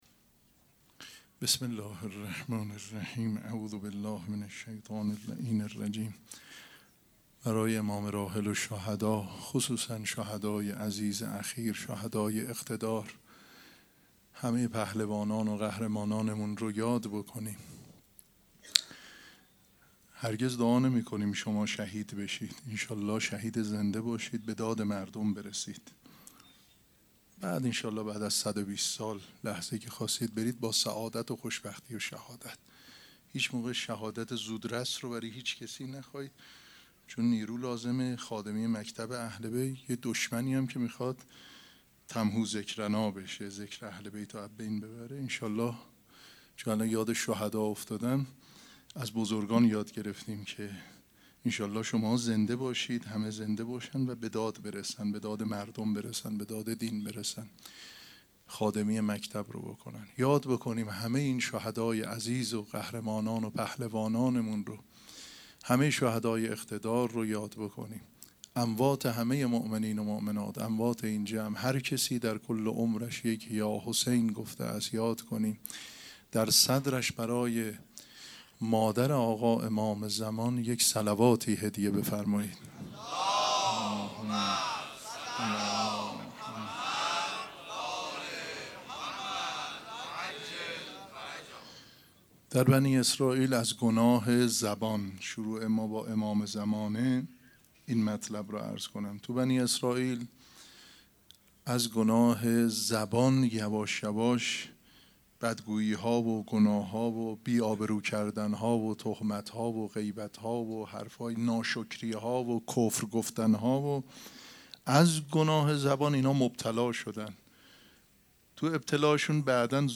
سخنرانی
مراسم عزاداری شام شهادت حضرت رقیه سلام الله علیها ‌‌‌‌‌‌‌‌‌‌چهارشنبه ۸ مرداد ۱۴۰۴ | ۵ صفر ۱۴۴۷ ‌‌‌‌‌‌‌‌‌‌‌‌‌هیئت ریحانه الحسین سلام الله علیها